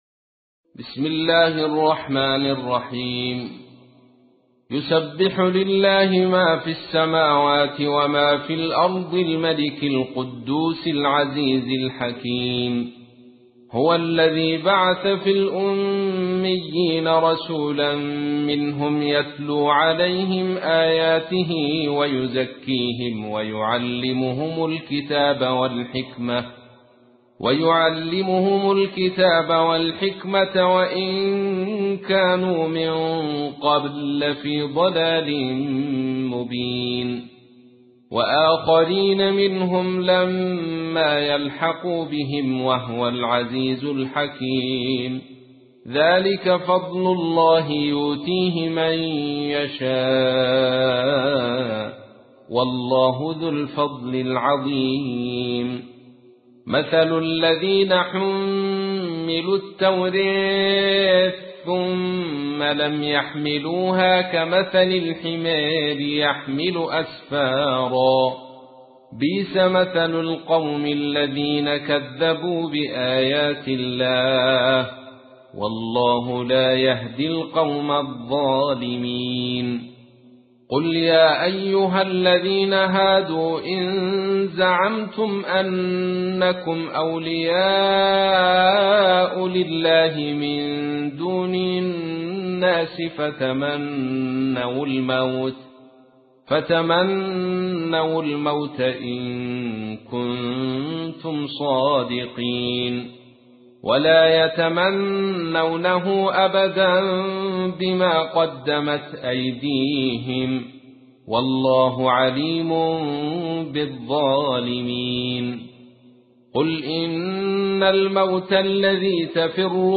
تحميل : 62. سورة الجمعة / القارئ عبد الرشيد صوفي / القرآن الكريم / موقع يا حسين